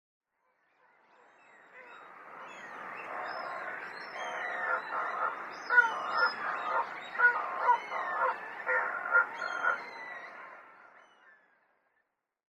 オオミズナギドリ
【分類】 ミズナギドリ目 ミズナギドリ科 オオミズナギドリ属 オオミズナギドリ 【分布】北海道(稀な旅鳥)、本州(稀な旅鳥)、四国(稀な旅鳥)、九州(稀な旅鳥) 【生息環境】北海道から九州までの周辺の島で繁殖 海上に生息 【全長】48cm 【主な食べ物】魚、イカ 【鳴き声】鳴きあい 雄 雌 【聞きなし】